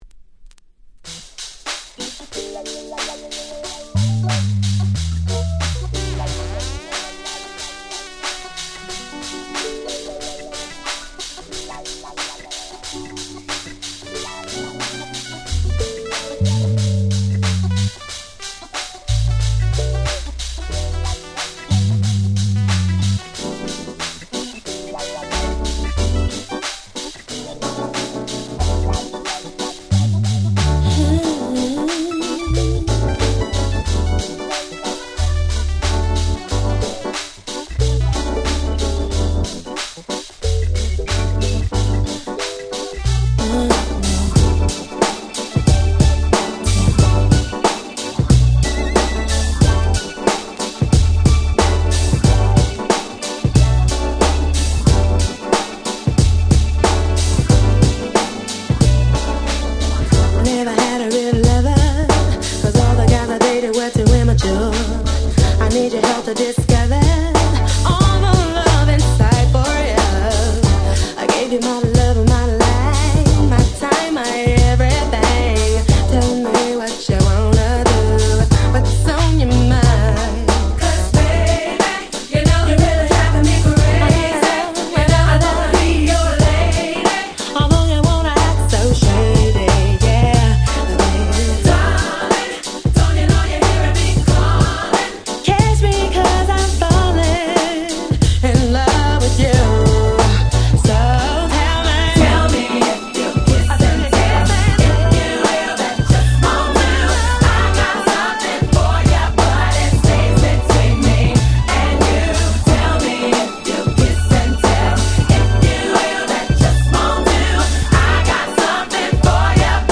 97' Nice R&B / Hip Hop Soul !!
90's ヒップホップソウル